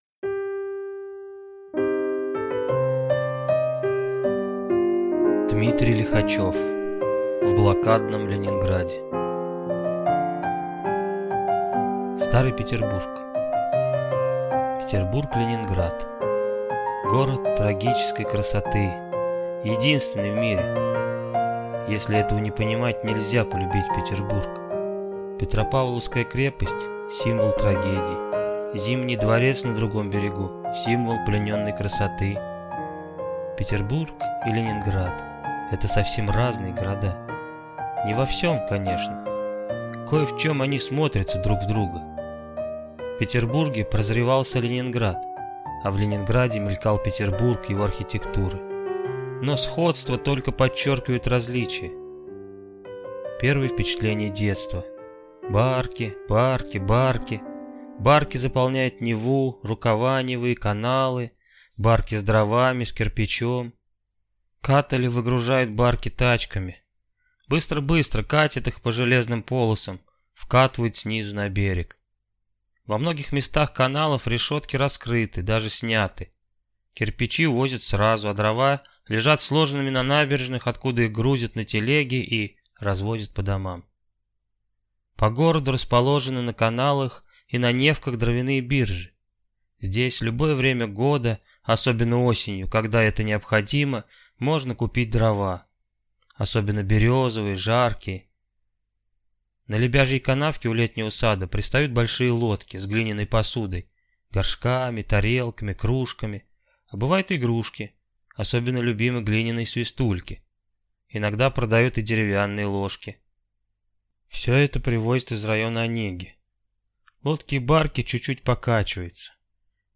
Аудиокнига В блокадном Ленинграде | Библиотека аудиокниг